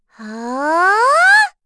Dosarta-Vox_Casting2.wav